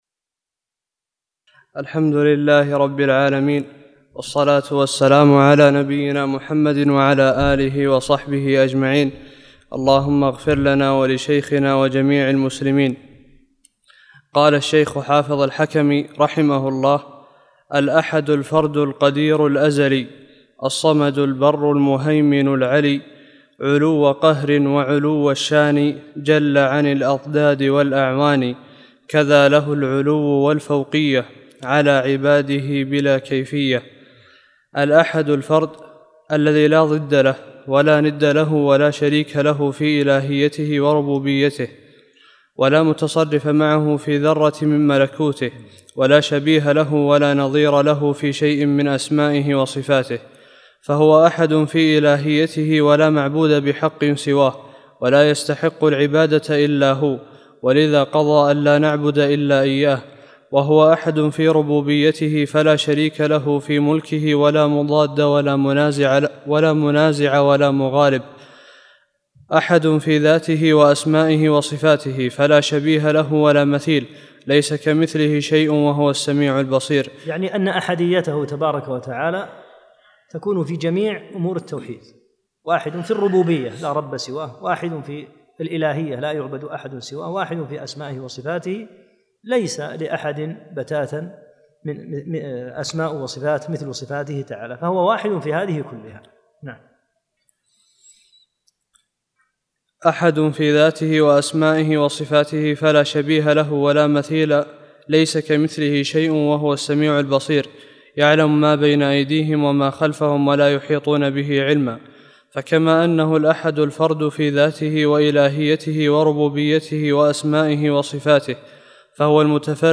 13- الدرس الثالث عشر